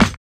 Snare (Figaro).wav